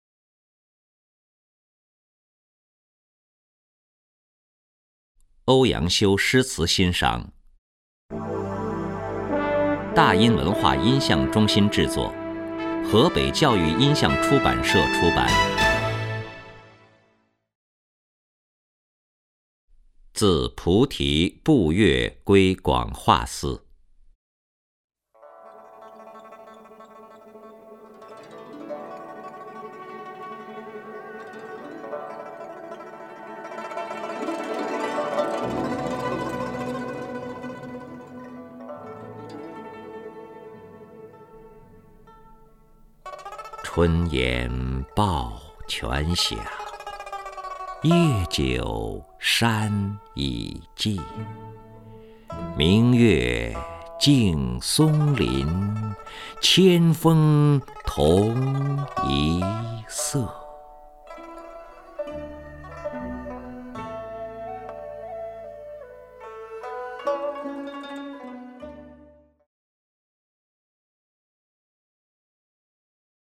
任志宏朗诵：《自菩提步月归广化寺》(（北宋）欧阳修)　/ （北宋）欧阳修
名家朗诵欣赏 任志宏 目录